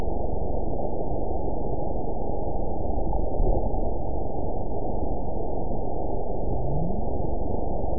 event 914429 date 05/08/22 time 23:45:07 GMT (3 years ago) score 8.98 location TSS-AB05 detected by nrw target species NRW annotations +NRW Spectrogram: Frequency (kHz) vs. Time (s) audio not available .wav